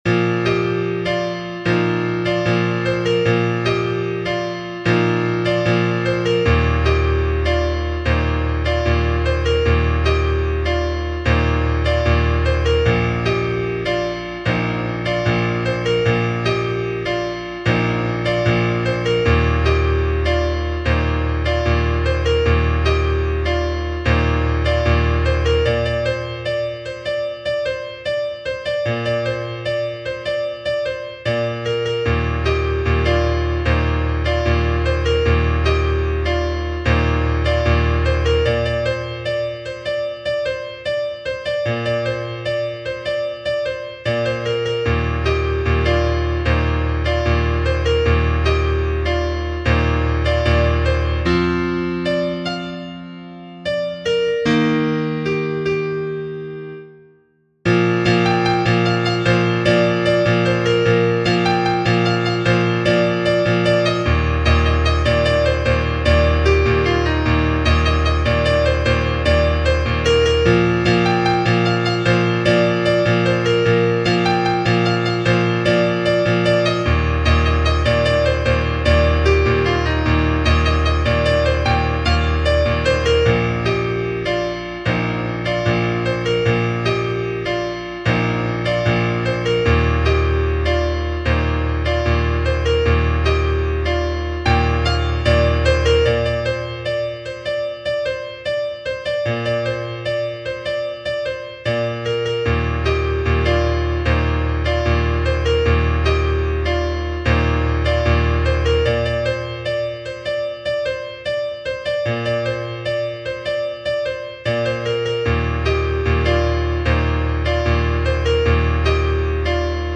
Thể loại 🎹 Piano
for Piano (Solo)